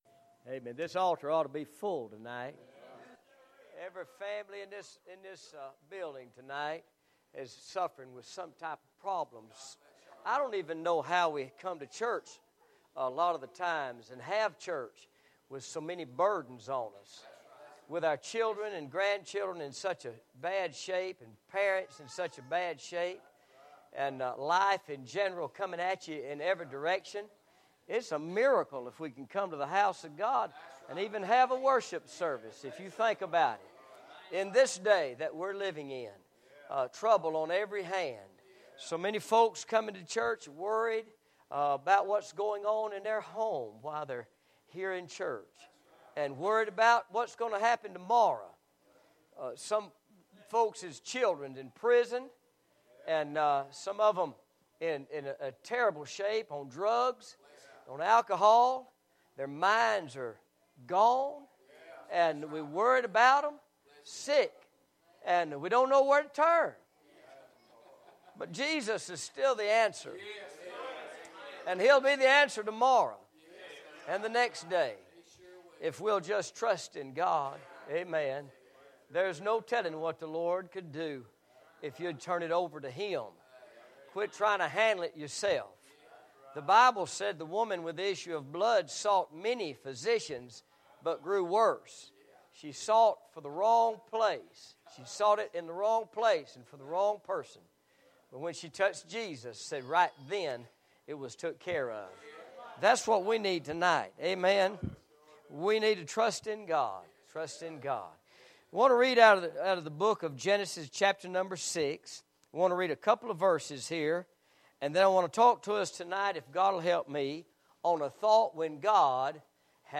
Message-When-God-Has-Had-Enough.mp3